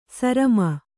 ♪ sarama